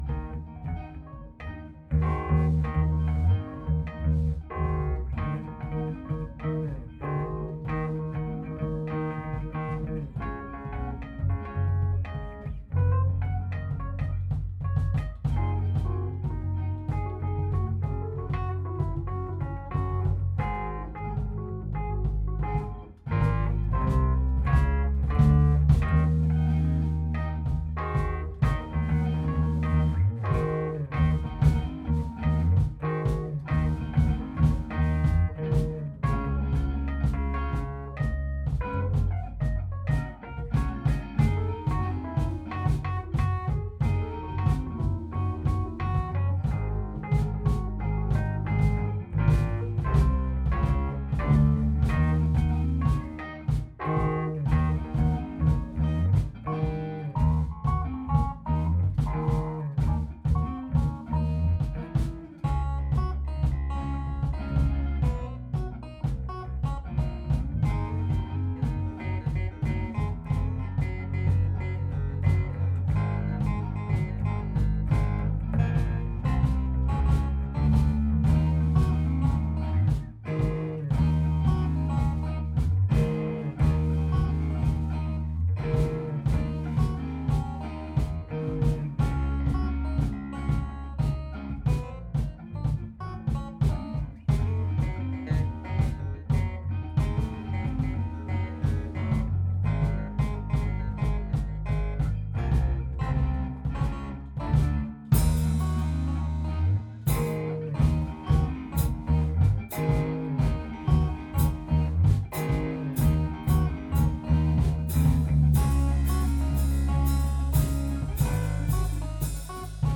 Jam